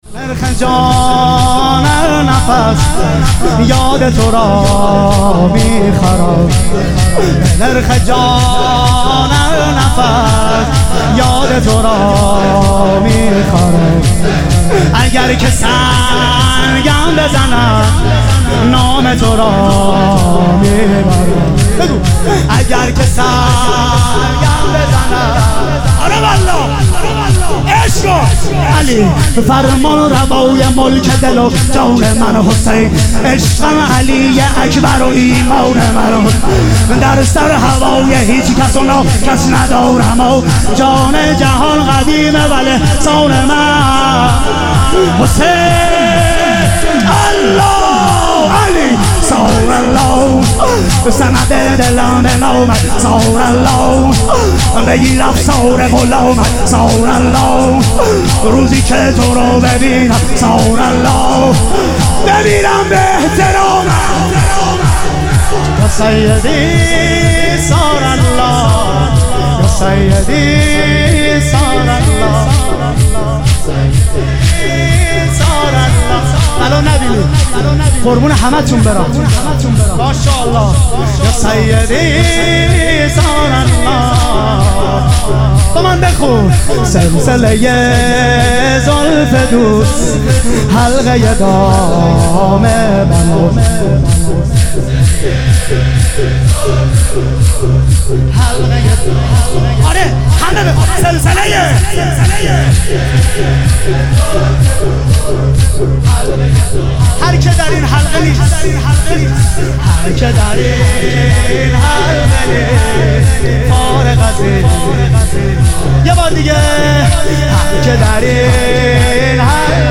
محرم الحرام - شور